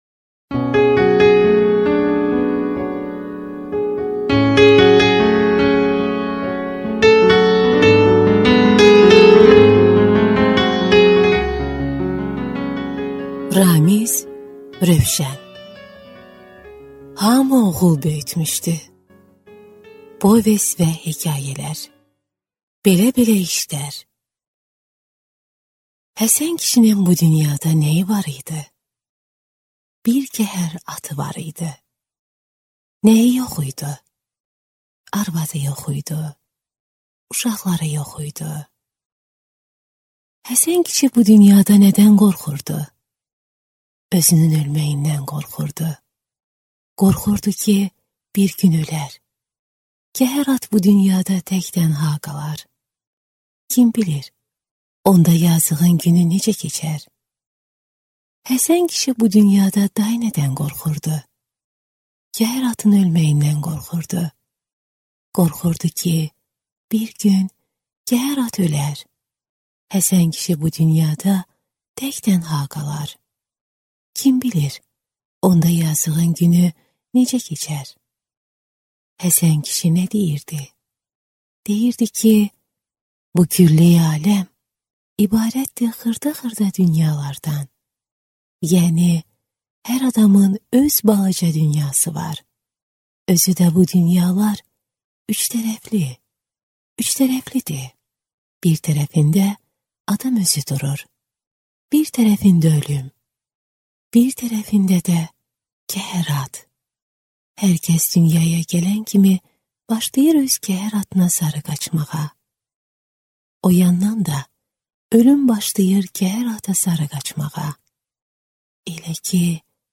Аудиокнига Hamı oğul böyütmüşdü | Библиотека аудиокниг